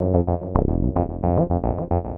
UR 303 acid bass 1 f.wav